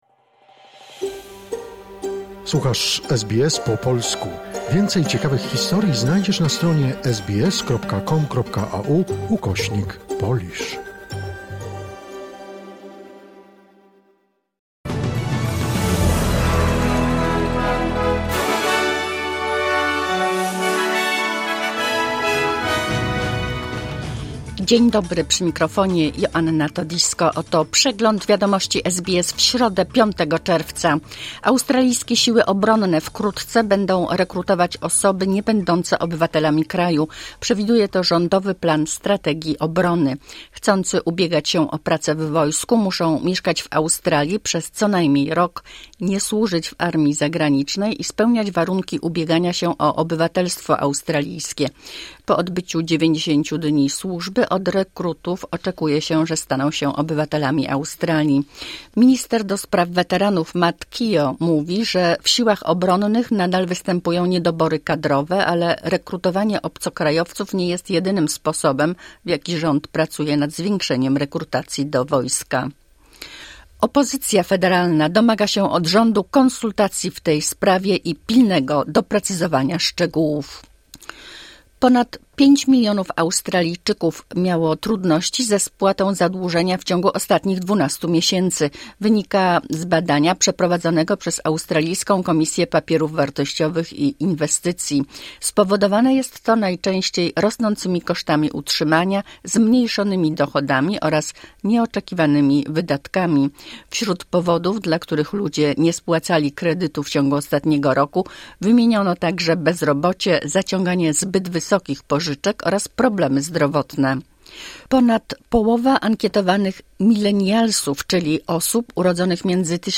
Skrót najważniejszych doniesień z Australii i ze świata, w opracowaniu polskiej redakcji SBS